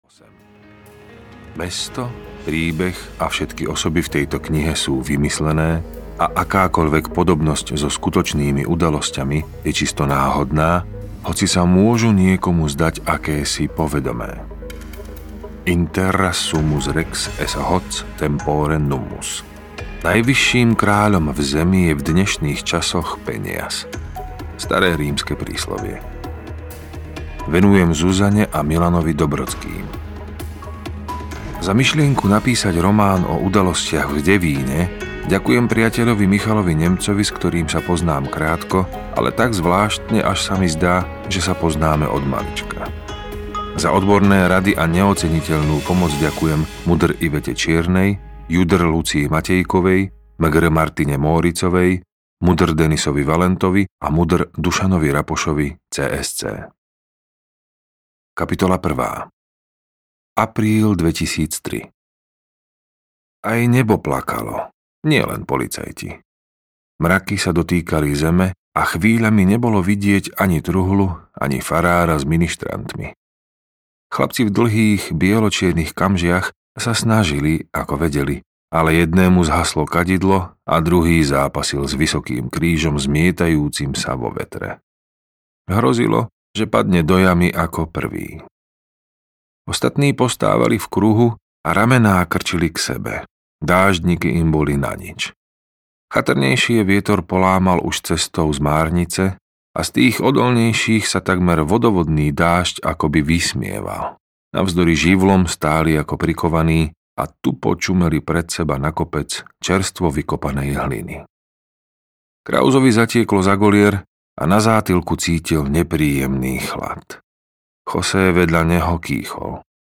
Knieža smrť audiokniha
Ukázka z knihy
knieza-smrt-audiokniha